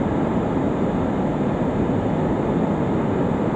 autoPilotLoop.wav